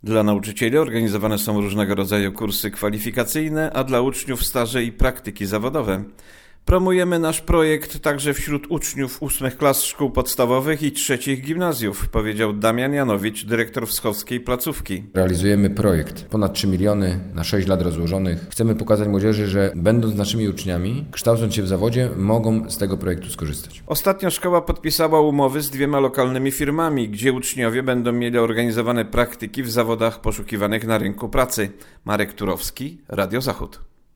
Środki przeznaczone są na realizację szkoleń, stażów i praktyk zawodowych oraz powiększenie bazy dydaktycznej. Relacja